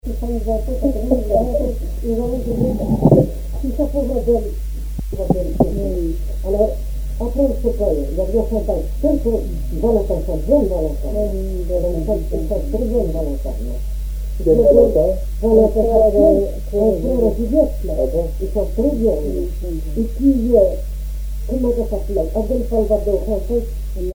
danse : ronde : grand'danse
Genre énumérative
Pièce musicale inédite